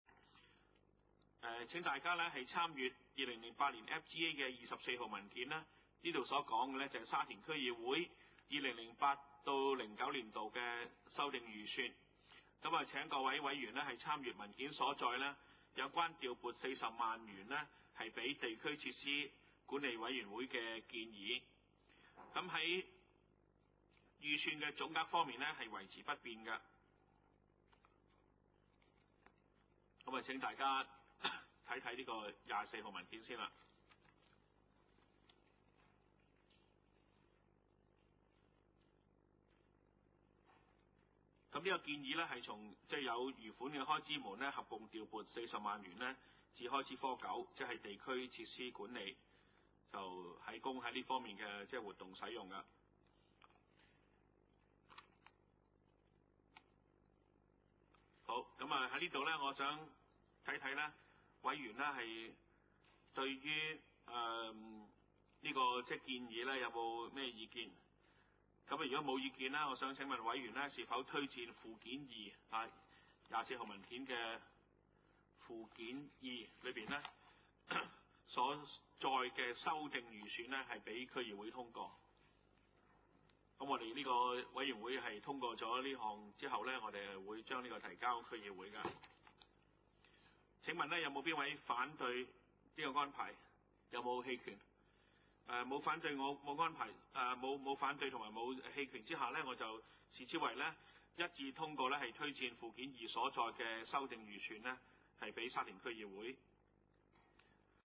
二零零八年第二次會議
: 沙田區議會會議室